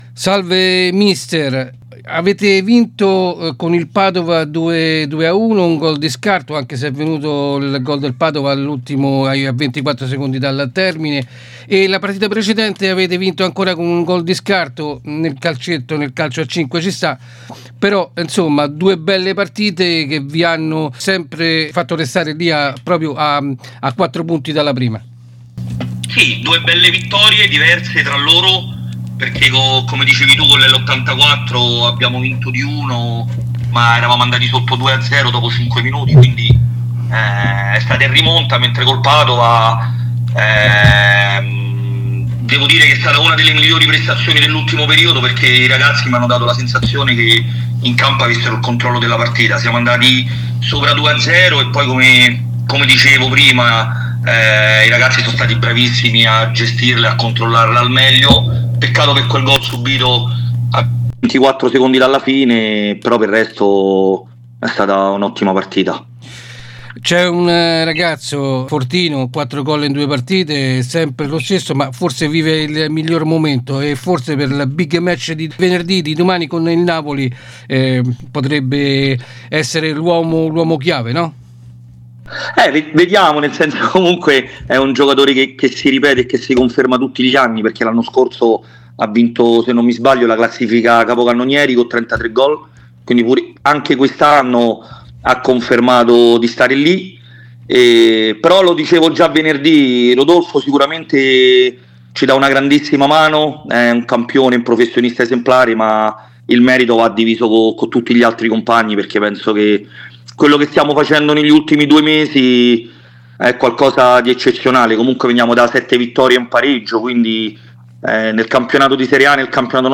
ESCLUSIVA FUTSAL